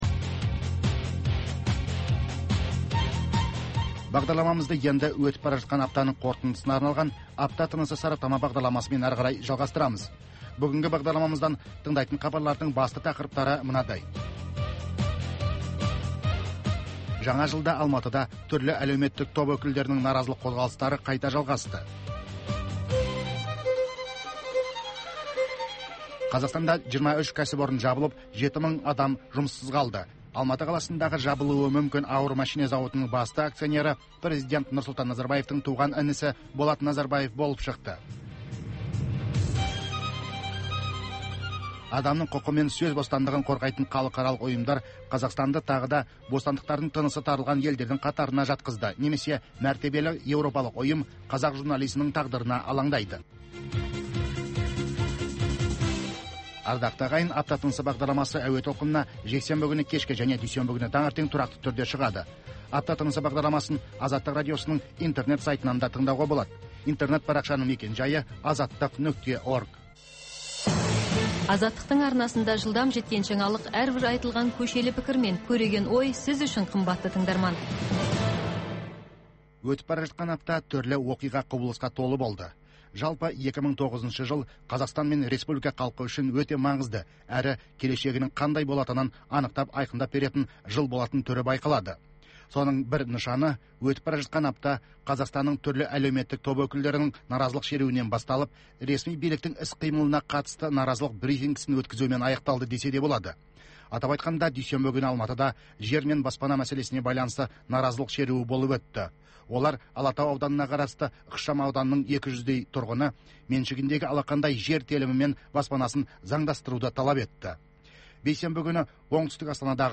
Апта ішінде орын алған елеулі оқиғалар мен өзгеріс, құбылыстар турасында сарапшылар талқылаулары, оқиға ортасынан алынған репортаждардан кейін түйіндеме, пікірталас, қазақстандық және халықаралық талдаушылар пікірі, экономикалық сараптамалар.